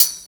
35 HAT+TMB-R.wav